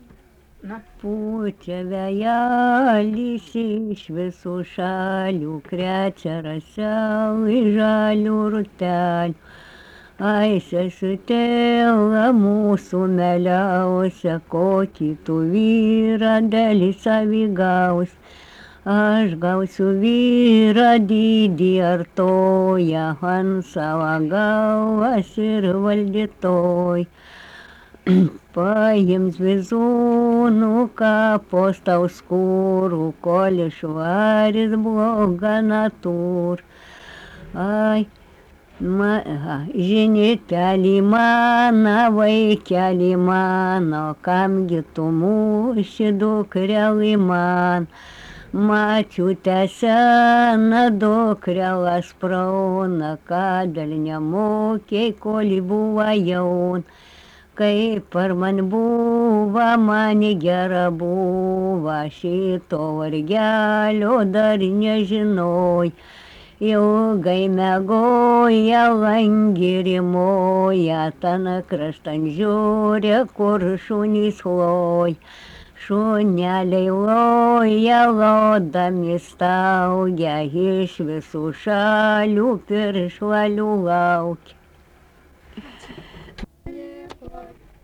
daina
Krapiškis
vokalinis